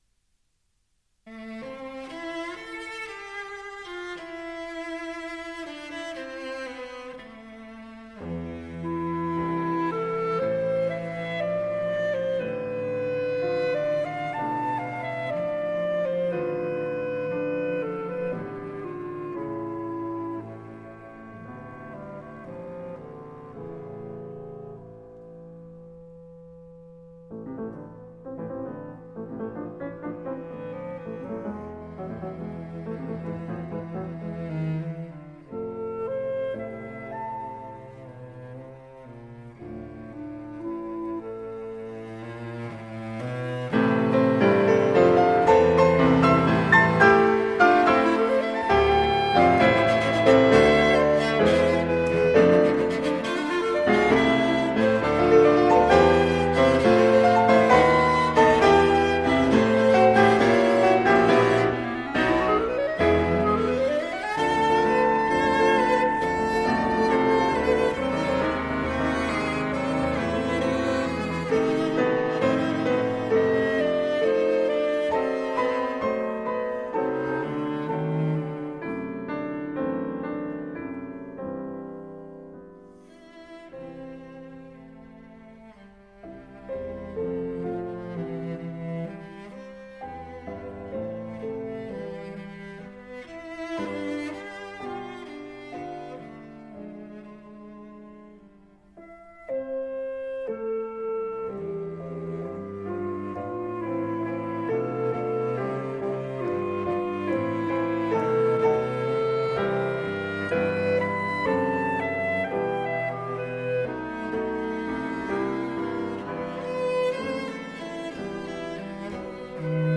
A group of three musicians.
Trio for Piano, Clarinet and Violoncello in A minor
Allegro